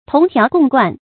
同條共貫 注音： ㄊㄨㄙˊ ㄊㄧㄠˊ ㄍㄨㄙˋ ㄍㄨㄢˋ 讀音讀法： 意思解釋： 條：枝條；貫：錢串。